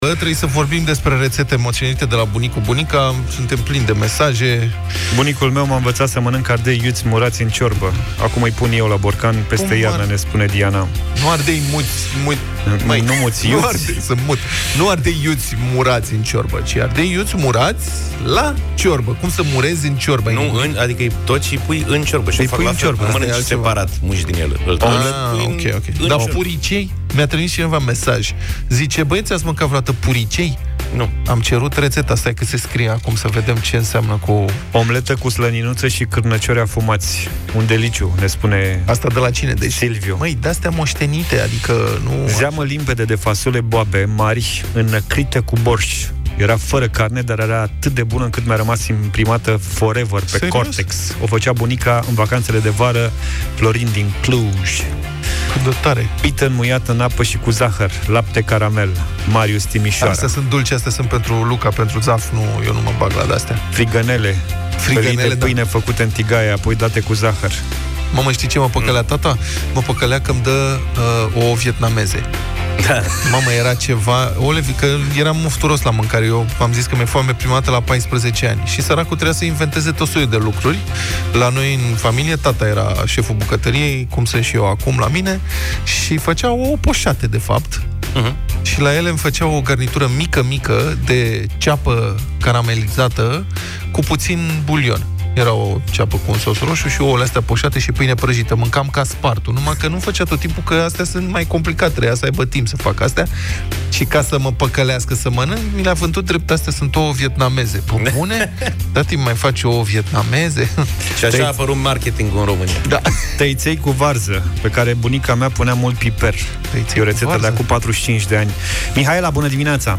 În această dimineaţă le-am cerut ascultătorilor să ne destăinuie câteva reţete pe care le au de la bunici.